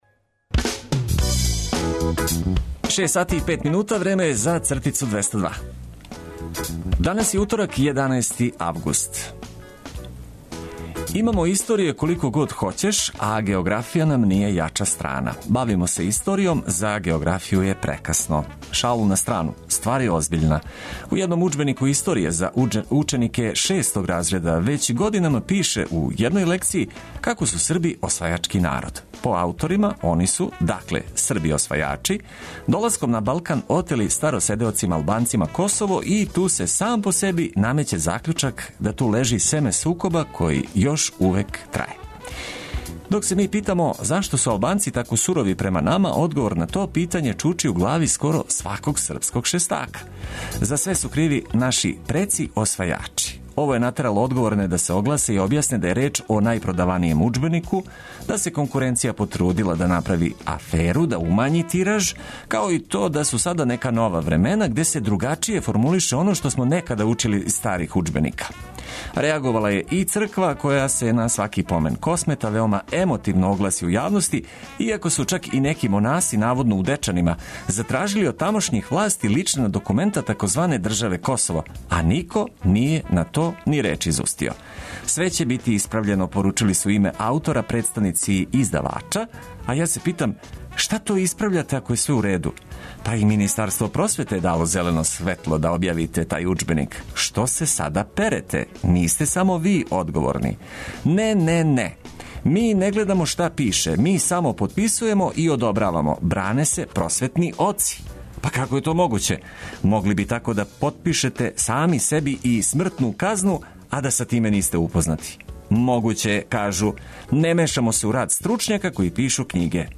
Корисне информације уз много добре музике - то је мото за заједничко дочекивање новог врелог дана.